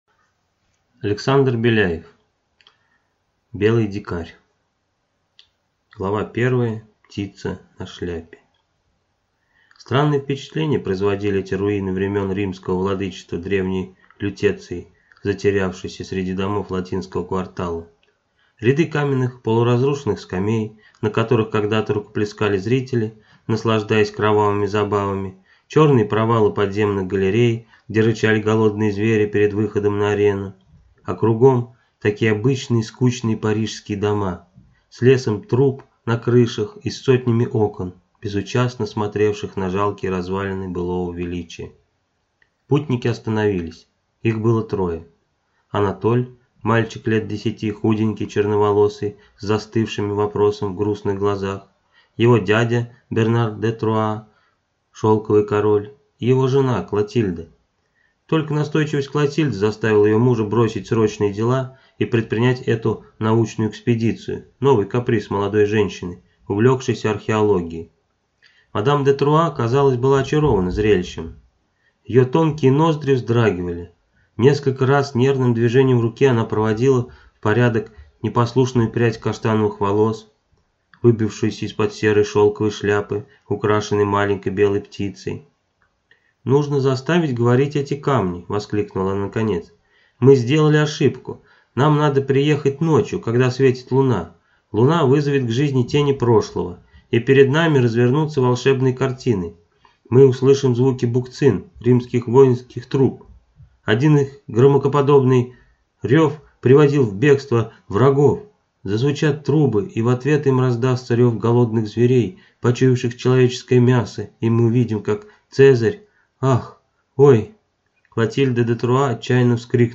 Аудиокнига Белый дикарь | Библиотека аудиокниг